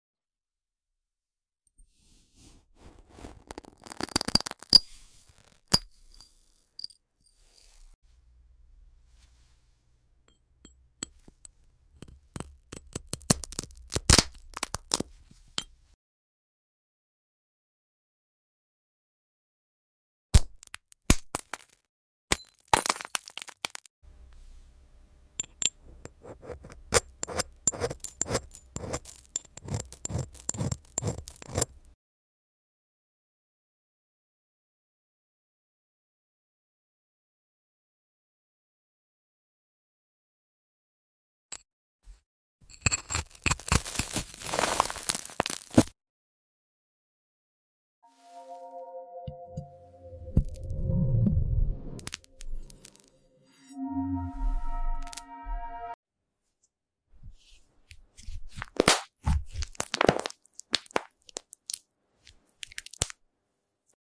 🔮 Crystal ASMR – Part sound effects free download
Tapping, scraping, and subtle resonance from crystal textures. No talking – just pure, glassy tingles for your ears and mind.